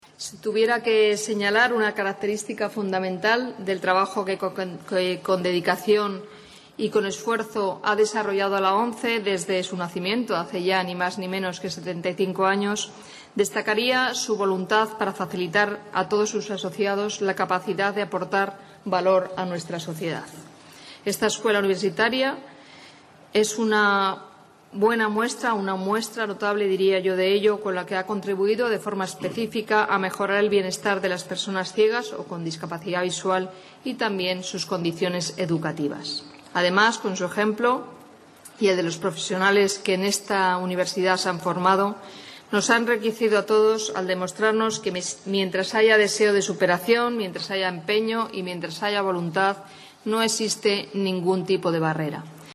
La ministra, que presidió este acto de celebración, comenzó destacando la capacidad de la ONCE para aportar valor a la sociedad, entre otras formas,